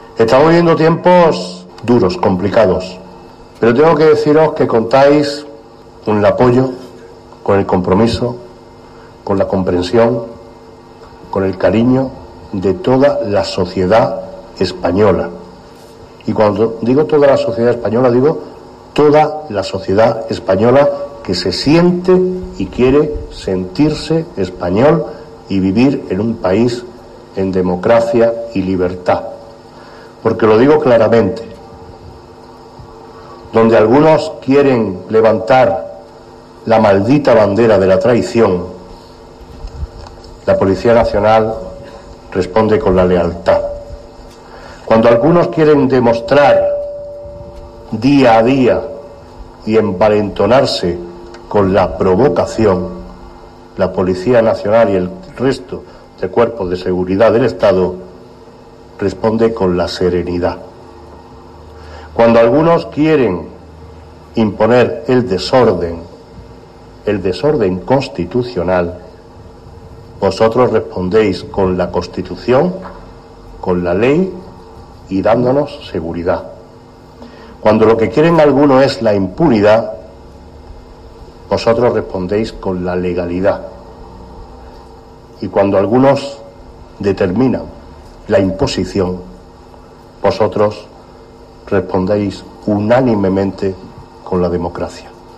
Cortes de voz M. Barón 652.5 kb Formato: mp3